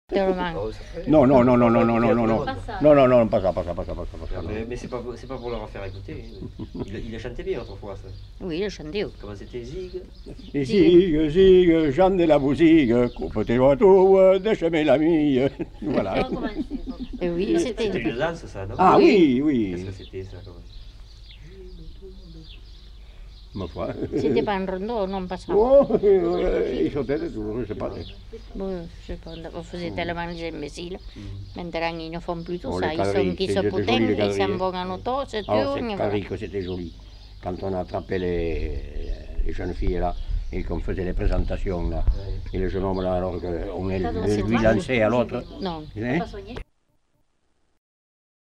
Aire culturelle : Bazadais
Lieu : Ruffiac
Genre : chant
Effectif : 1
Type de voix : voix d'homme
Production du son : chanté
Classification : danses